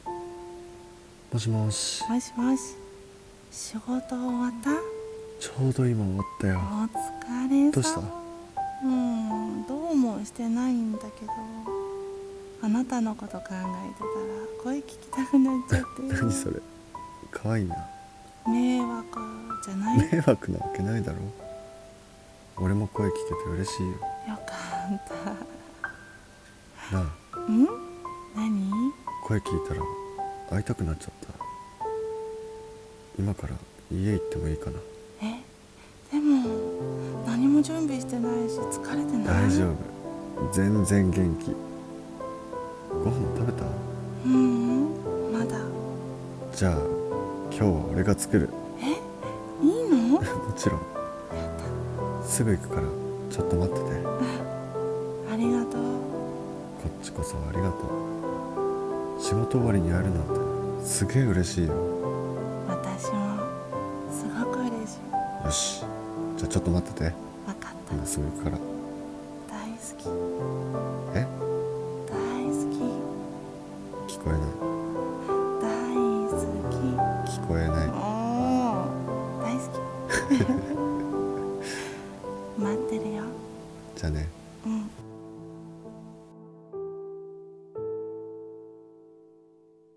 声劇【電話～声が聴きたくて～】 旦那×嫁